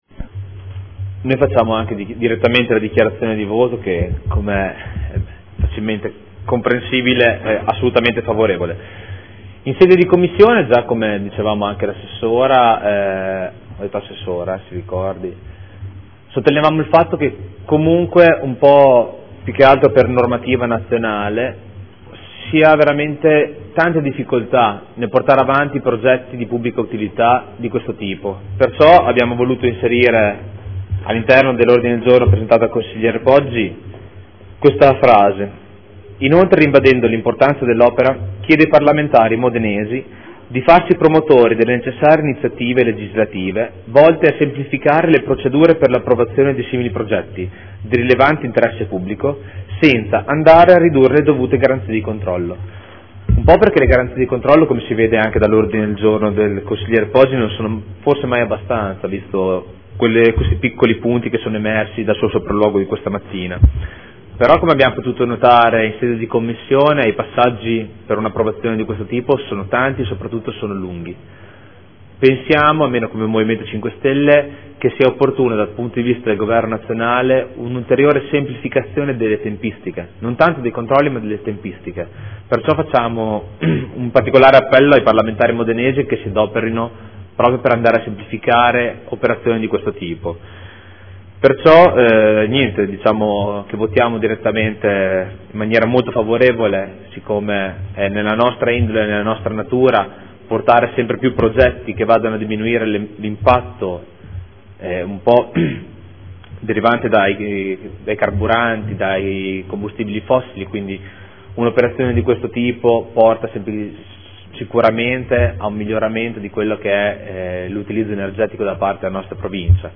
Seduta del 09/03/2015 Progetto di impianto idroelettrico sul Fiume Panaro, Località San Donnino, Comune di Modena, proponente Società DGM Srl – Espressione in merito alla valutazione di impatto ambientale (VIA), alla variante al vigente Piano Regolatore Comunale (POC) ed all’opposizione del vincolo preordinato all’esproprio – Dibattito sulla mozione n°30746